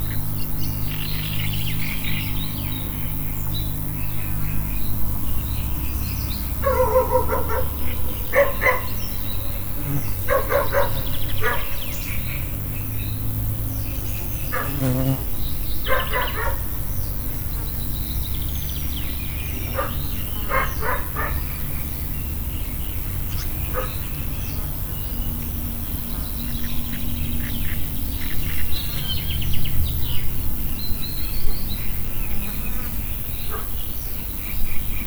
Directory Listing of /_MP3/allathangok/termeszetben/rovarok_premium/
kutyaesrovarok_rocso_tiszababolna00.35.wav